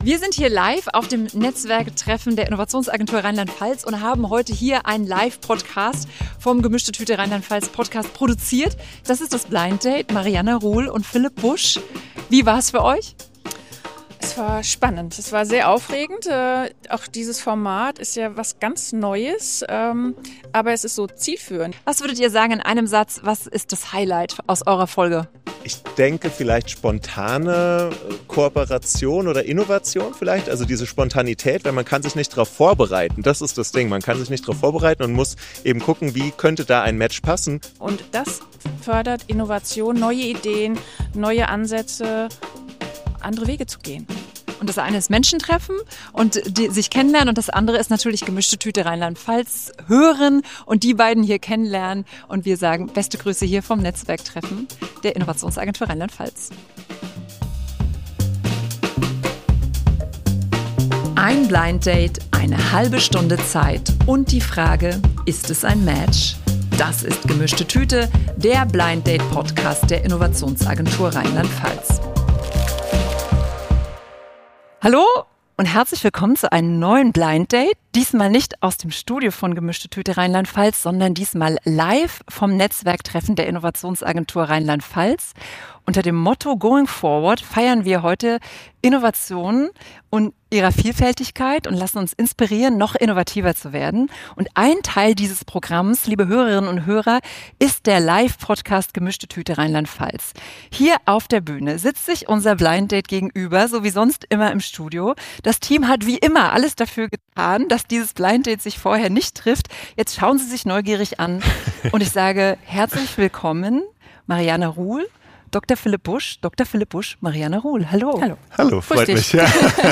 Folge 5 ist als Live Podcast beim Going Forward Event der Innovationsagentur RLP entstanden.